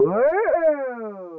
NFF-ya-whoa.wav